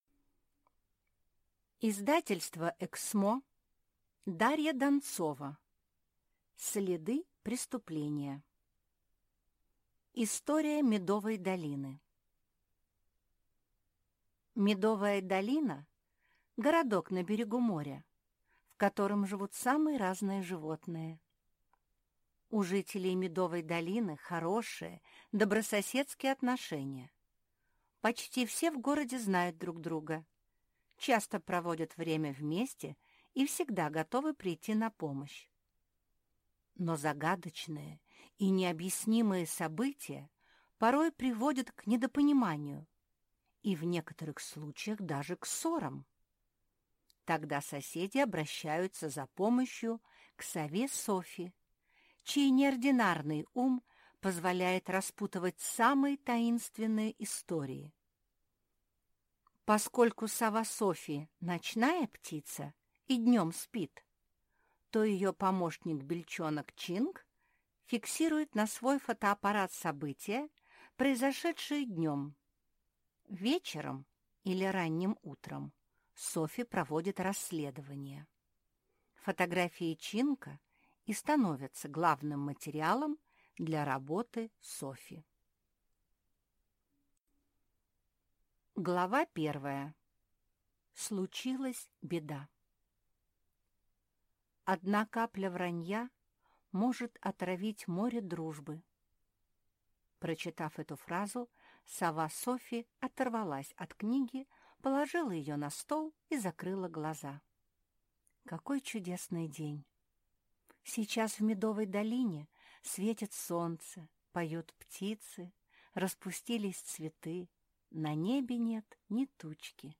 Аудиокнига Следы преступления | Библиотека аудиокниг